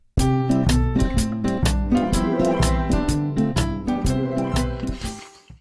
Короткая мелодия в начале сериала